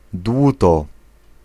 Ääntäminen
US : IPA : [ˈtʃɪz.əl]